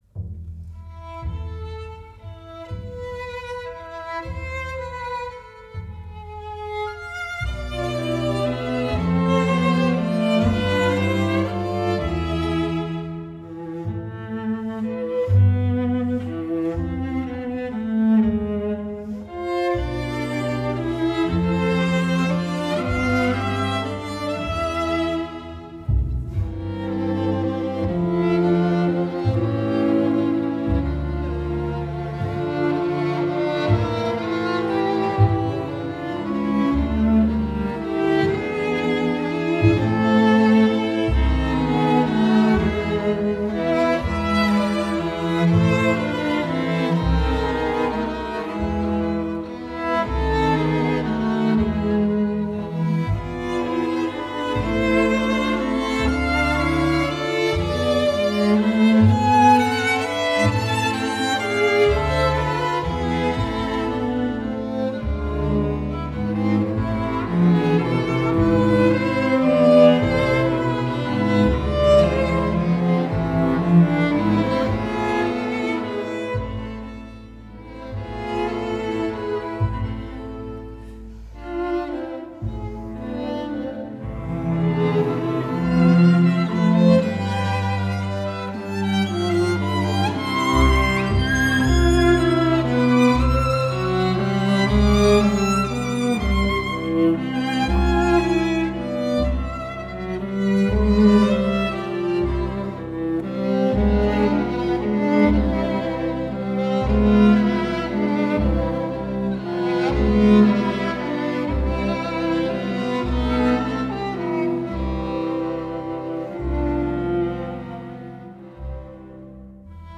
Filmmusik
mit einem Streichquartett einspielte